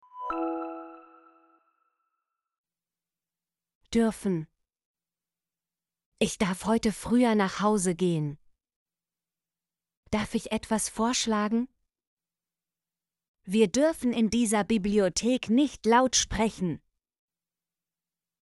dürfen - Example Sentences & Pronunciation, German Frequency List